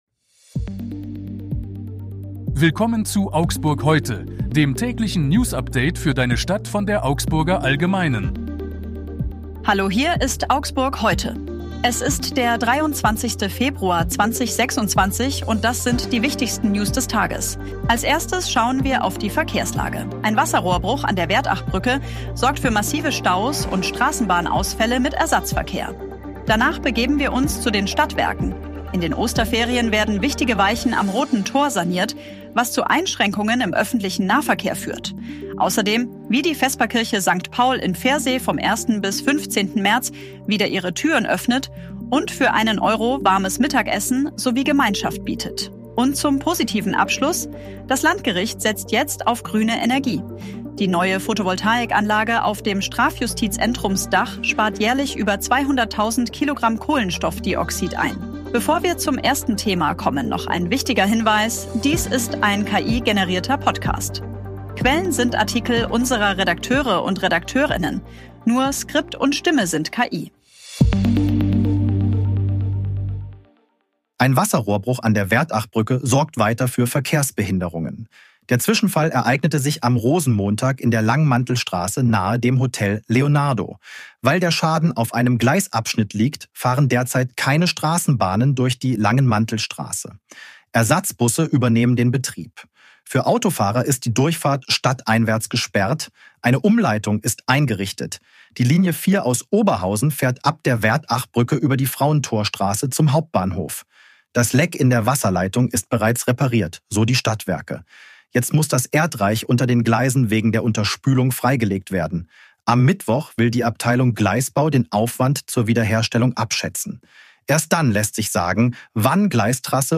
Dies ist ein KI-generierter Podcast.
Nur Skript und Stimme sind KI.